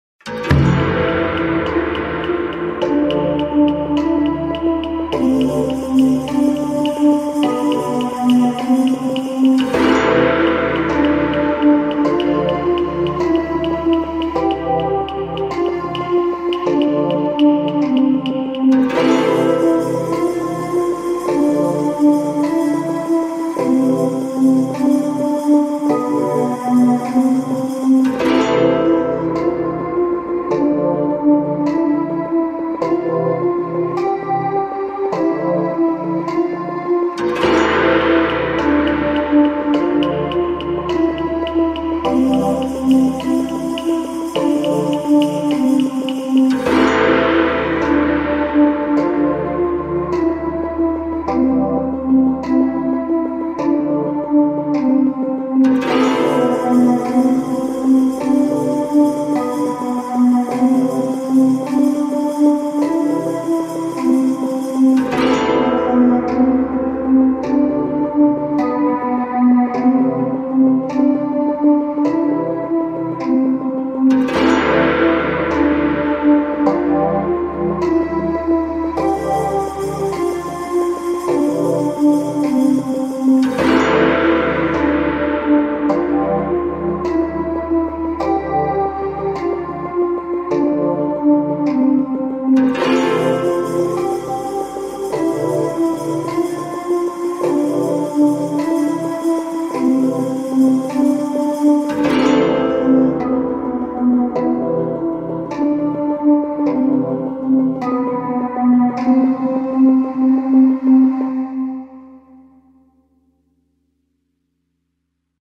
BGM
スローテンポロング